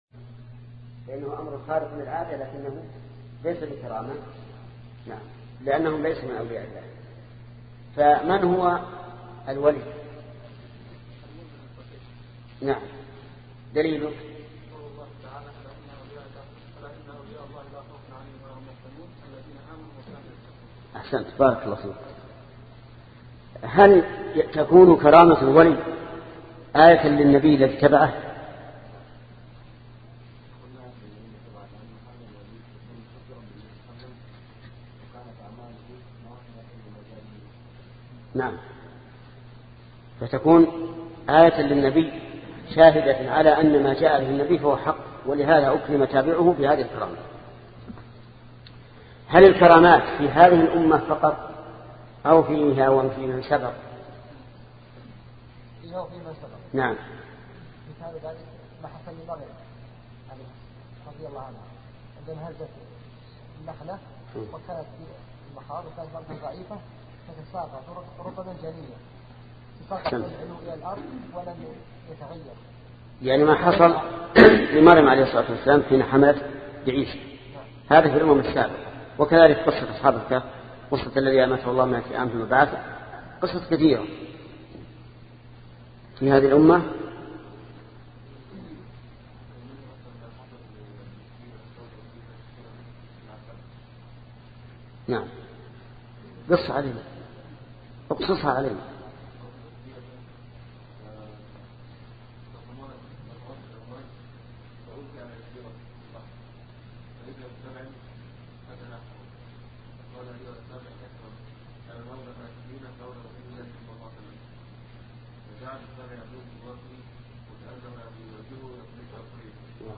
سلسلة مجموعة محاضرات شرح العقيدة السفارينية لشيخ محمد بن صالح العثيمين رحمة الله تعالى